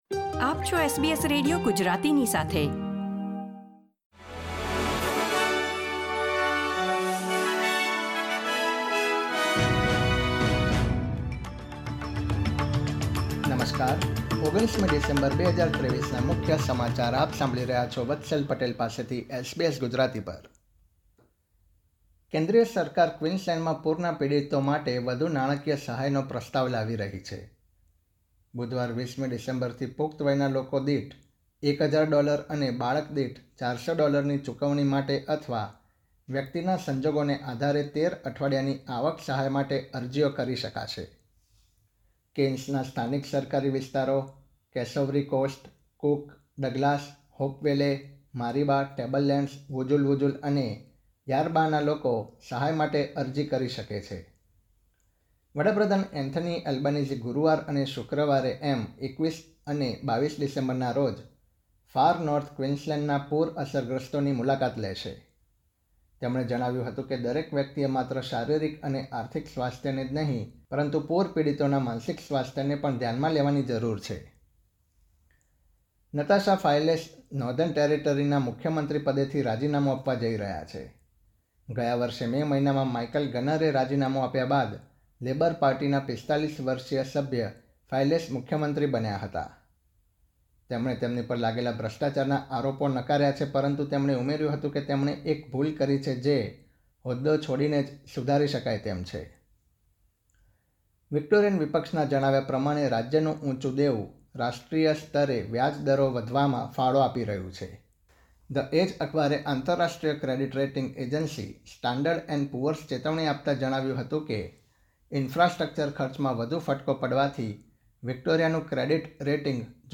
SBS Gujarati News Bulletin 19 December 2023